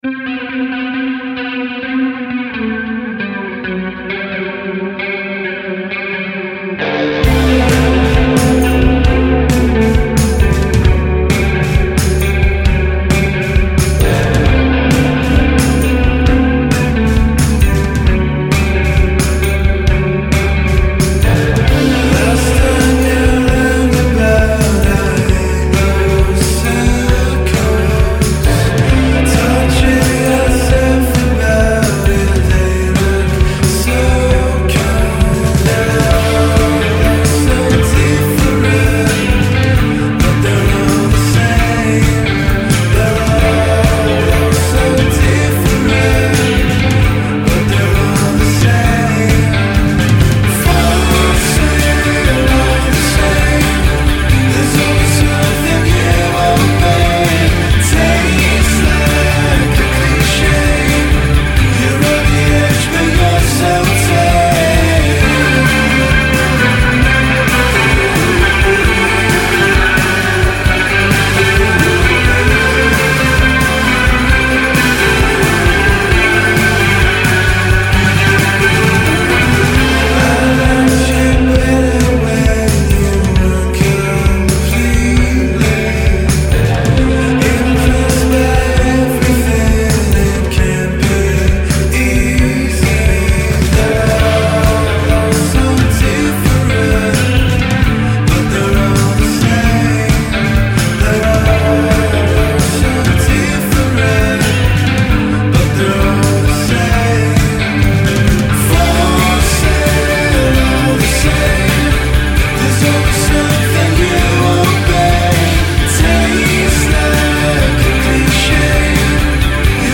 psych trio